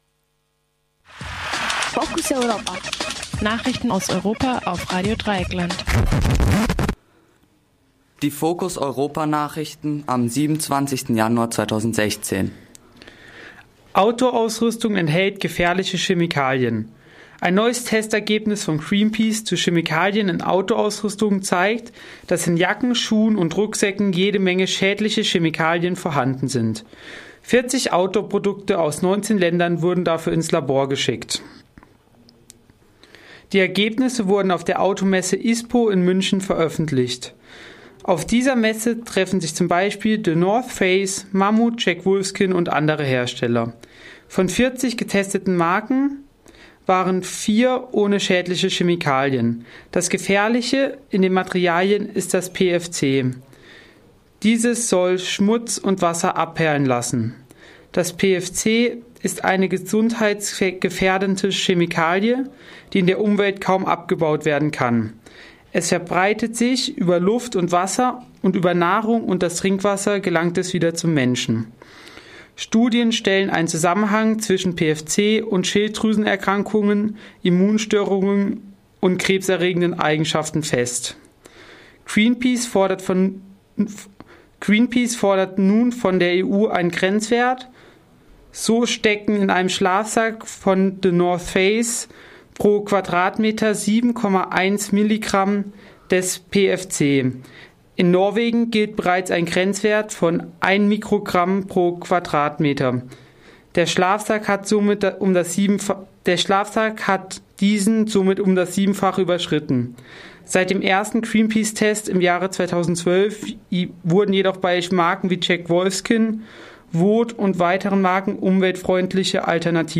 Focus Europa Nachrichten Mittwoch 27. Januar 2016